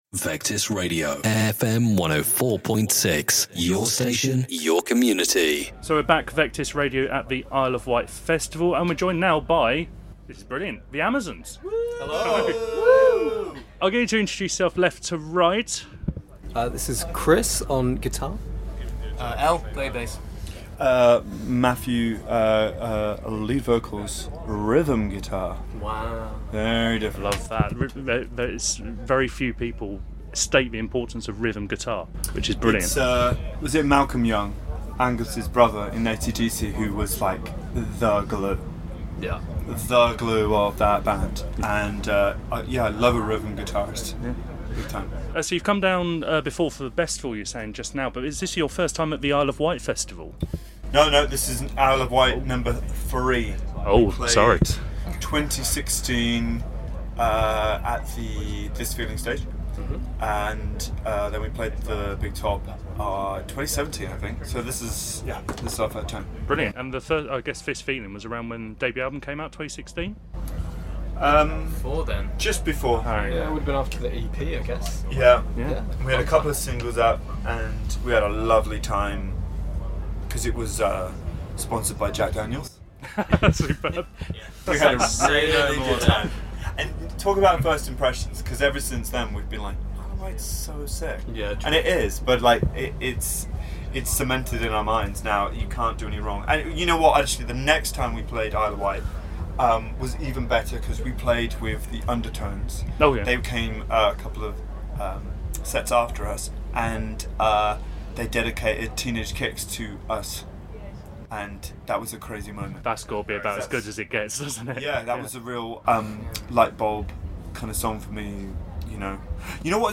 Vectis Radio were lucky enough to catch up with The Amazons prior to their Isle of Wight Festival Big Top set on Saturday. With two top 10 albums under their belts and a third record on the way, the band talk about their new track, Isle of Wight memories and the longing for a pandemic pint.